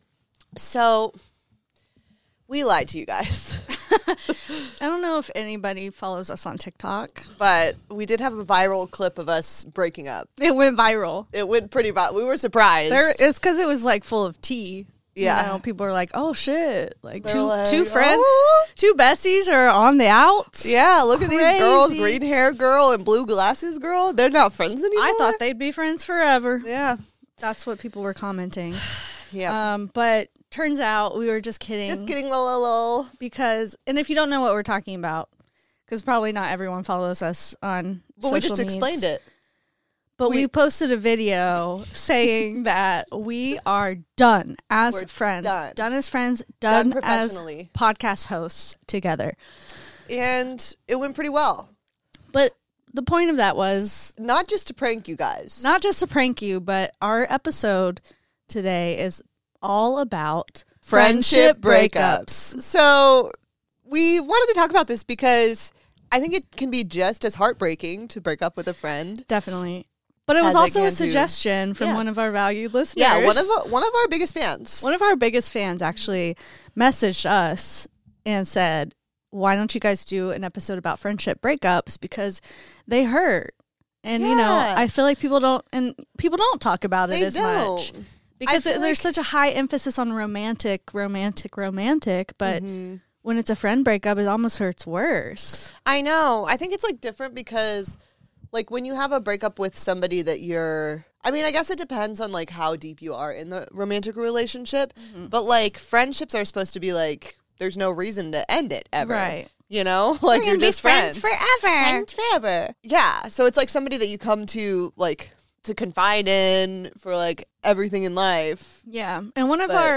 We were able to interview some bar patrons for this episode and they tell us some of their worst friend breakup stories. Some were betrayals, some were a classic case of ghosting without any explanation.